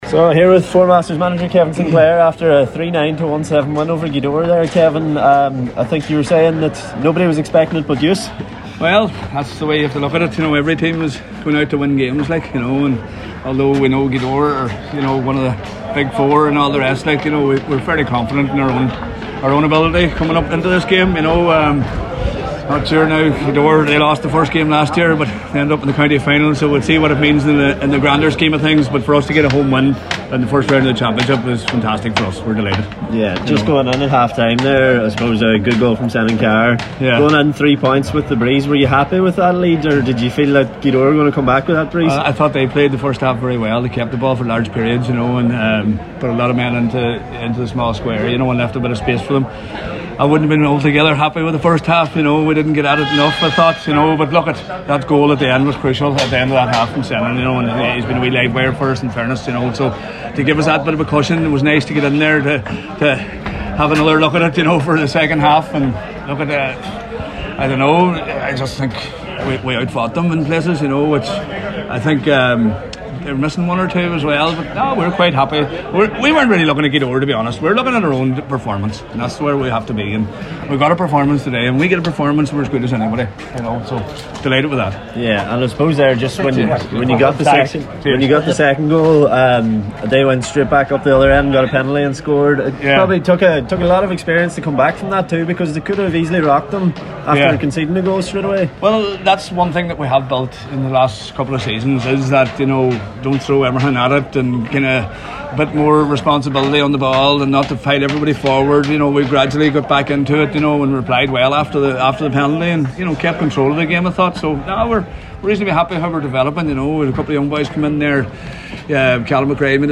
at full time in Donegal Town…